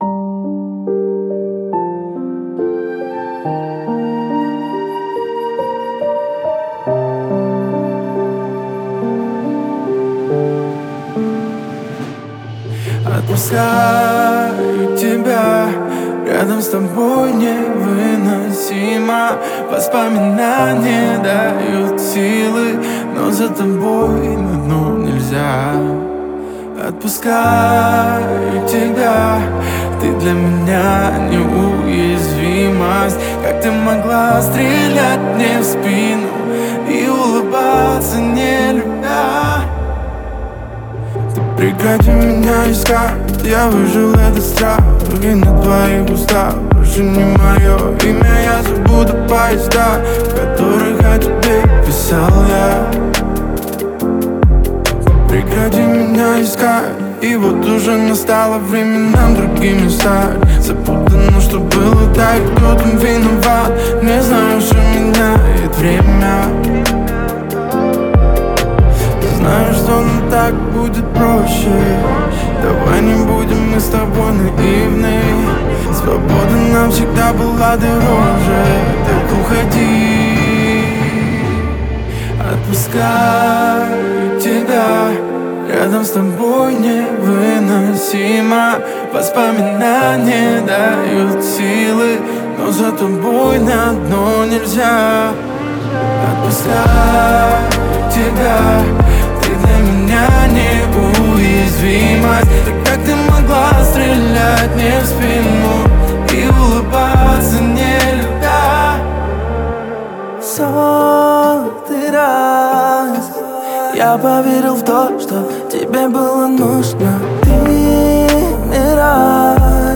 это эмоциональная композиция в жанре поп